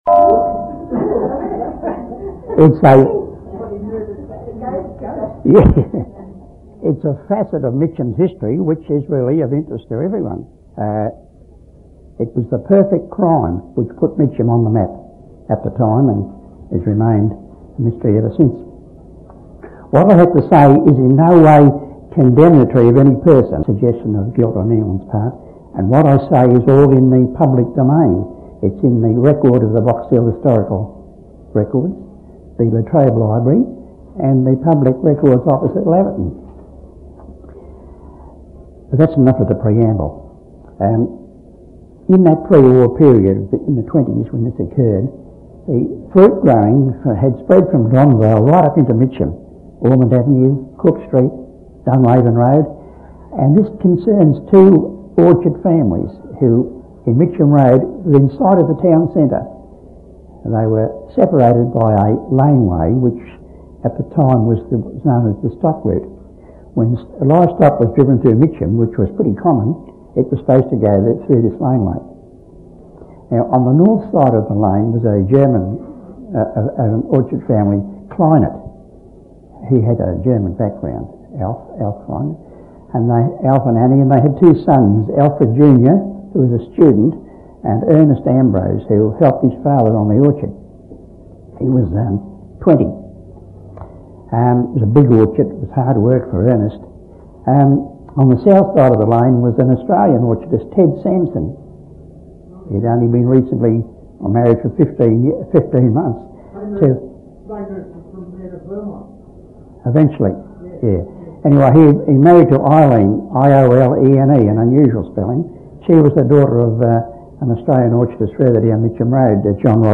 Mixed media - Audio Cassette, Mitcham Murder, 1/06/1998 12:00:00 AM
at the Whitehorse Historical society in June 1998